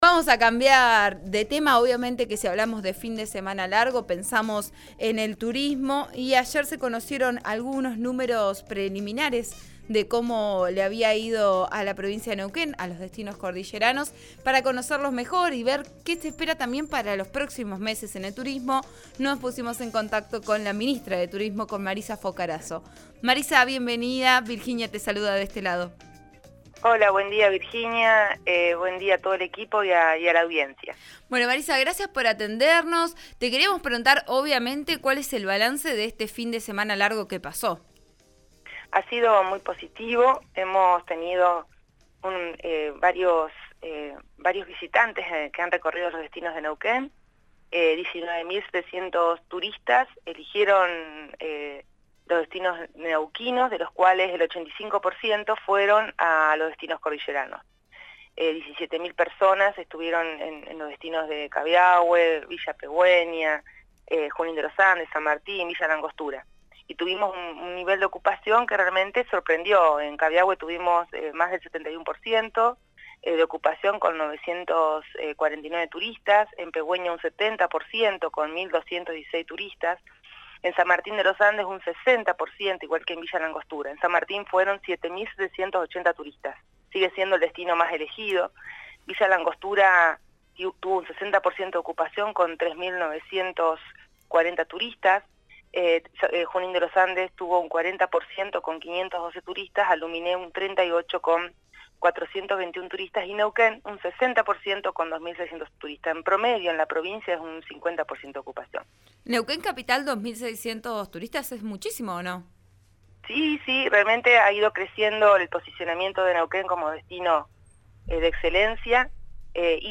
La ministra de Turismo, Marisa Focarazzo, explicó las complejidades de la apertura de la frontera terrestre en una entrevista que dio a «Vos A Diario» (RN RADIO 89.3).